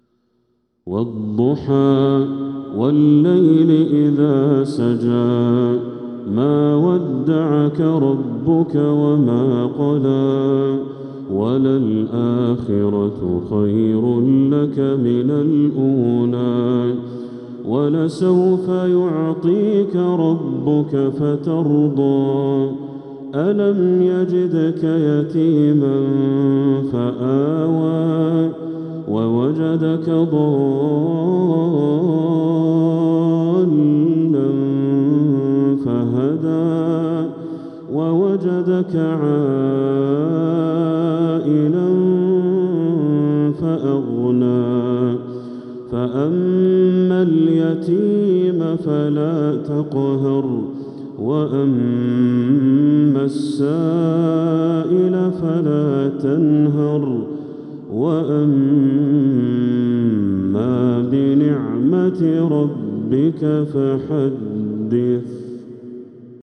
سورة الضحى كاملة | صفر 1447هـ > السور المكتملة للشيخ بدر التركي من الحرم المكي 🕋 > السور المكتملة 🕋 > المزيد - تلاوات الحرمين